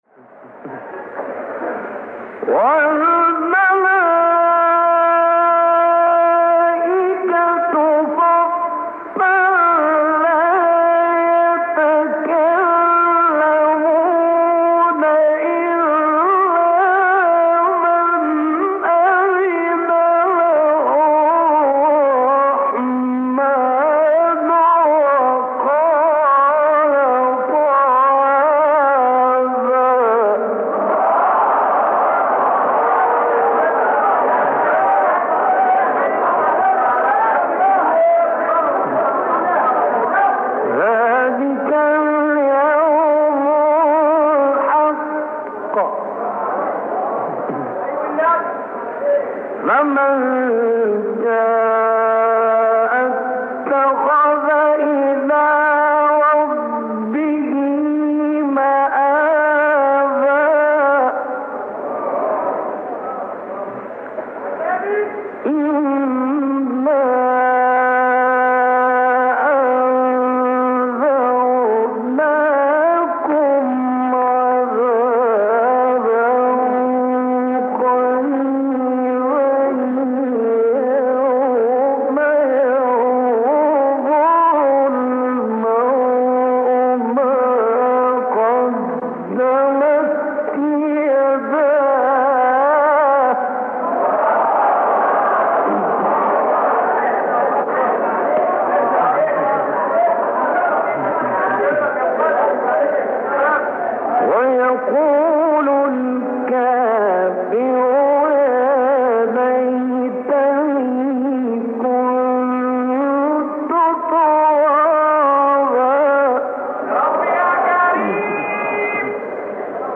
آیه 38-40 سوره نبأ و تلاوت سوره نصر استاد غلوش | نغمات قرآن | دانلود تلاوت قرآن